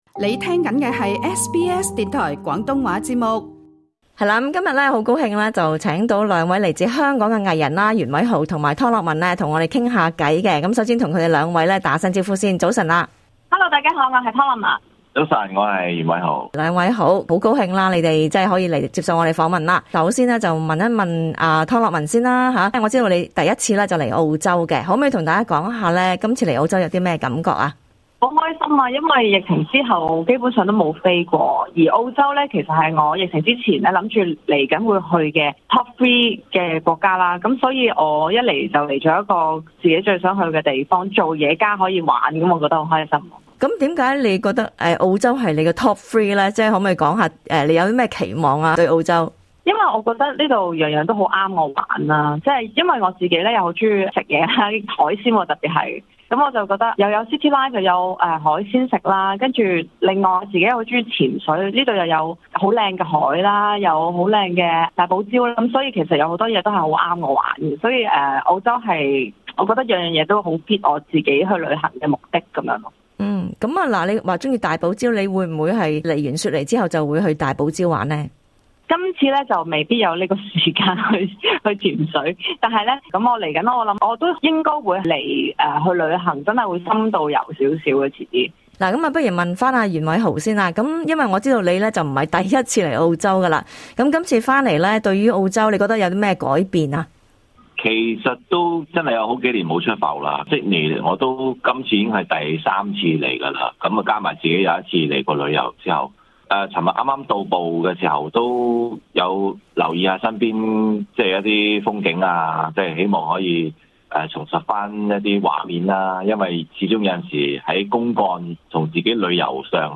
最近，香港無綫電視兩位藝人袁偉豪和湯洛雯亦前來澳洲參加一項嘉年華活動，SBS 廣東話節目亦訪問了他們。兩人會談談這次來澳洲有什麼感受和他們在新冠疫情下，究竟工作有沒有受到影響？